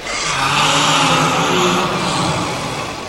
• GASPING WITH ECHO.wav
GASPING_WITH_ECHO_24G.wav